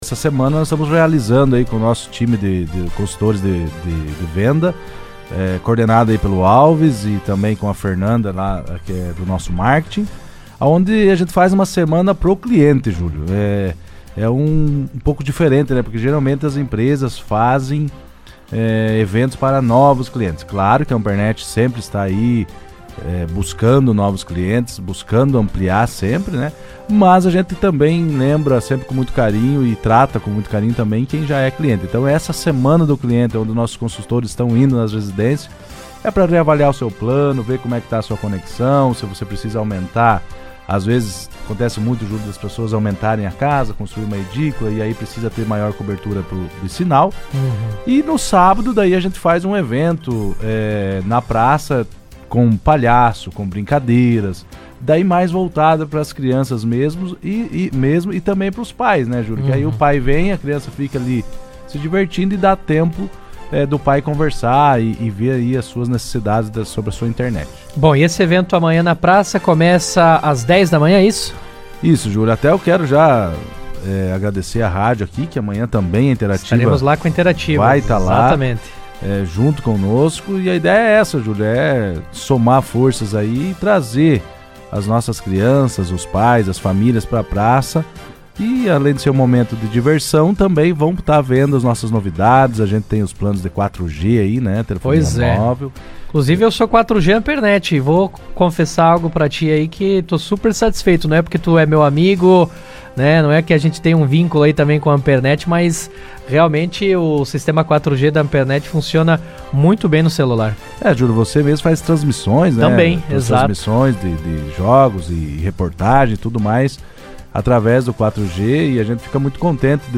participou do Jornal RA 2ª Edição e falou do trabalho realizado durante a semana e da ação que será realizada neste sábado.